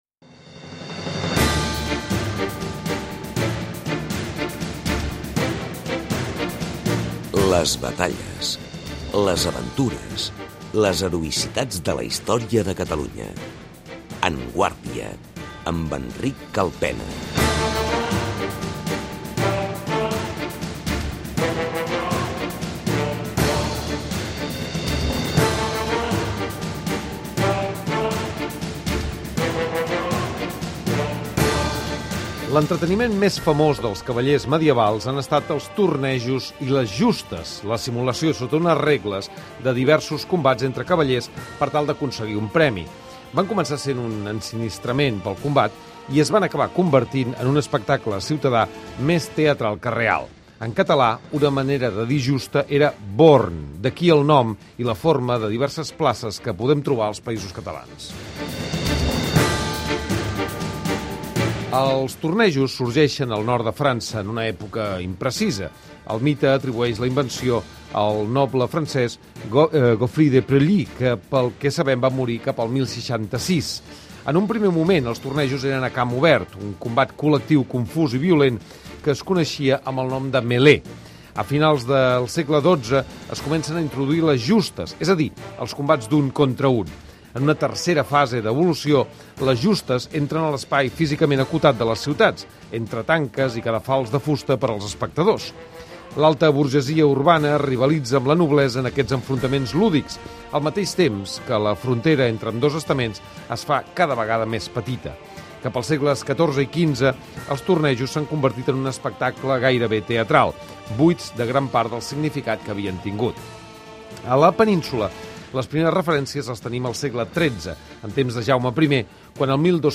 Descripció Careta del programa, espai dedicat als tornejos i les justes.
Gènere radiofònic Divulgació